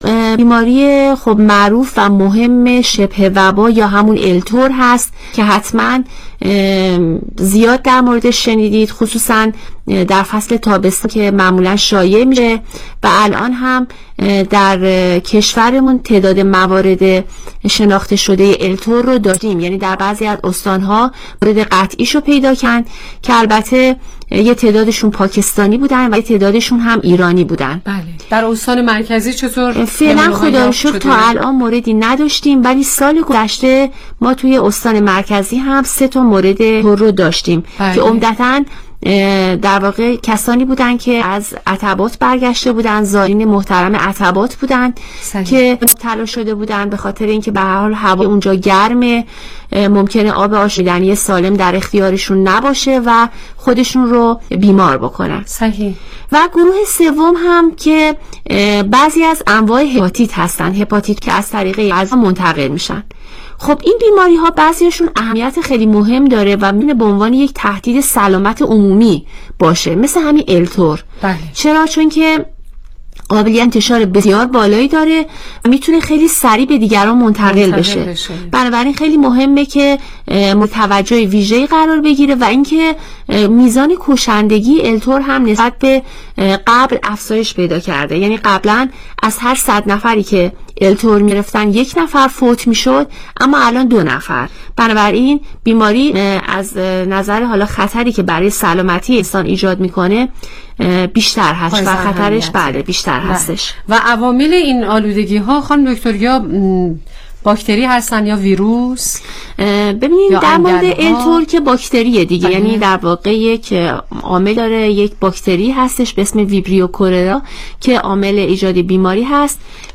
برنامه رادیویی کانون مهر با موضوع بیماری های منتقله از آب وغذا